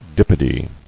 [dip·o·dy]